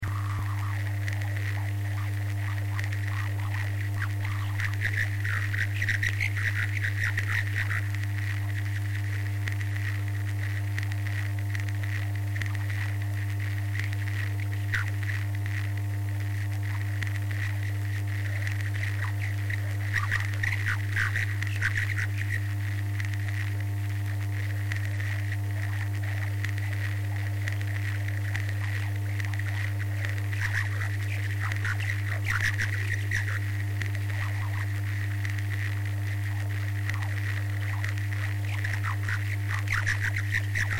دانلود آهنگ موج 8 از افکت صوتی طبیعت و محیط
دانلود صدای موج 8 از ساعد نیوز با لینک مستقیم و کیفیت بالا
جلوه های صوتی